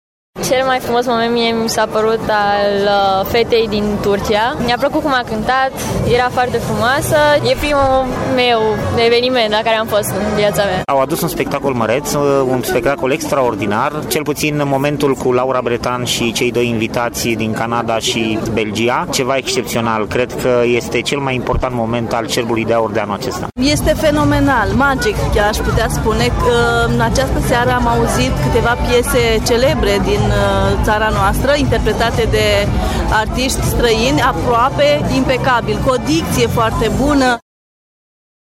La final publicul s-a declarat fascinat de interpretarile artistilor din prima seară a festivalului international Cerbul de Aur:
VOXURI-CERB-PRIMA-SEARA.mp3